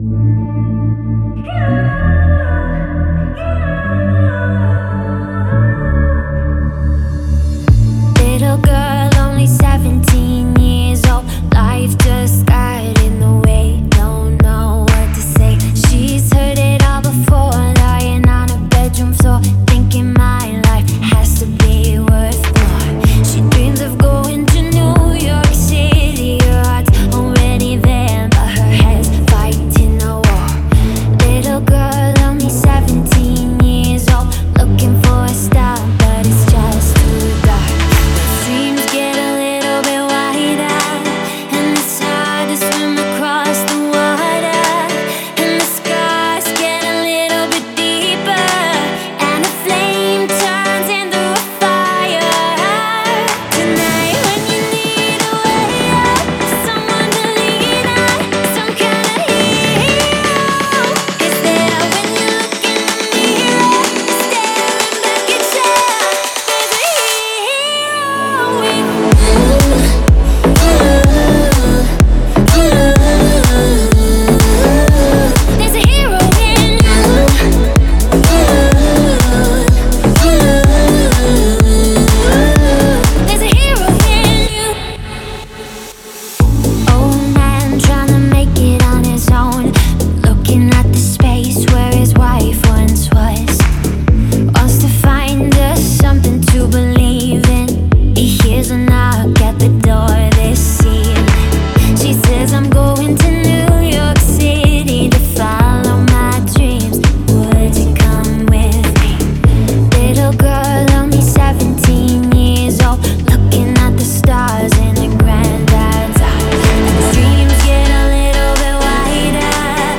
это энергичная танцевальная композиция